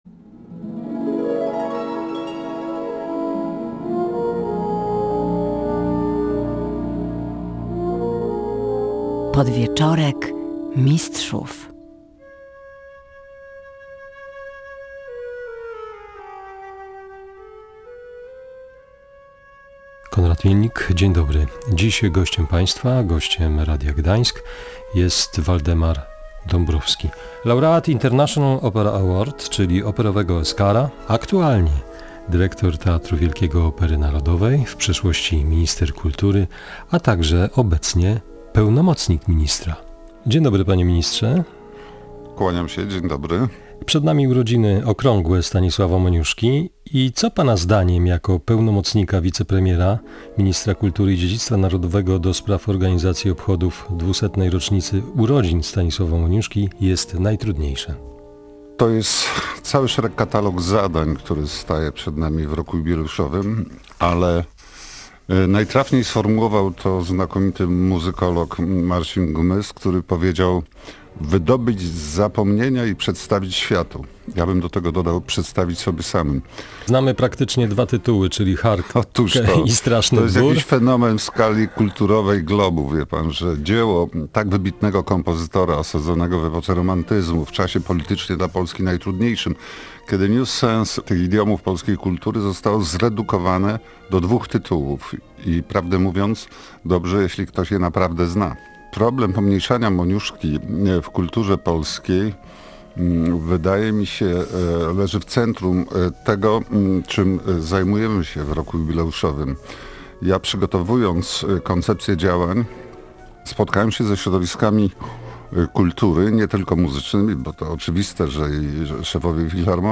To nie do pomyślenia, że sens idiomów polskiej kultury został zredukowany tylko do wspomnianych dzieł – mówił gość Radia Gdańsk.